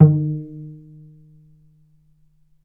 vc_pz-D#3-mf.AIF